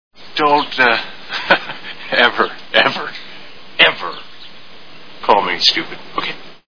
A Fish Called Wanda Movie Sound Bites